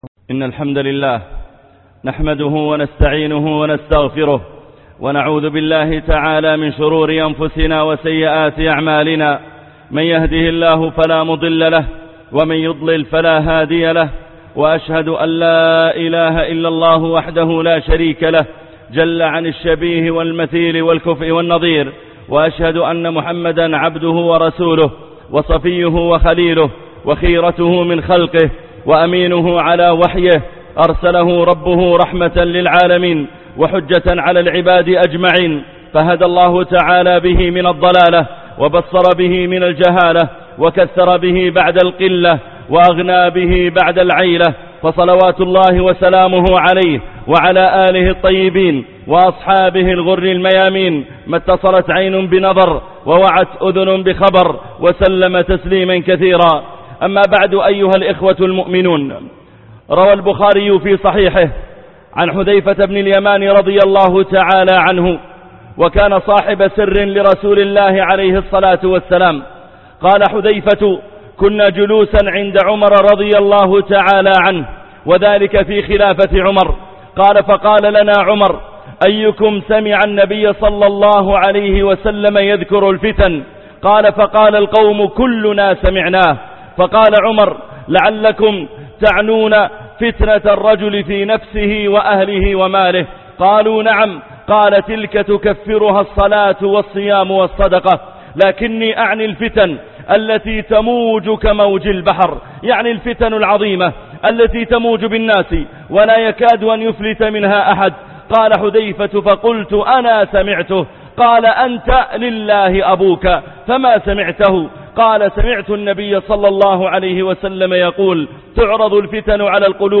الثبات على الدين 2 - خطب الجمعه - الشيخ محمد العريفي